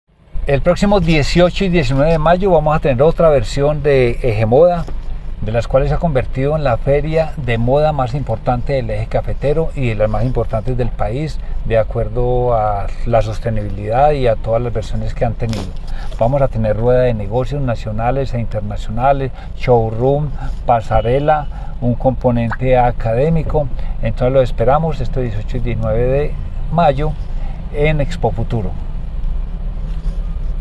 Audio_Secretario_de_Desarrollo_Economico.mp3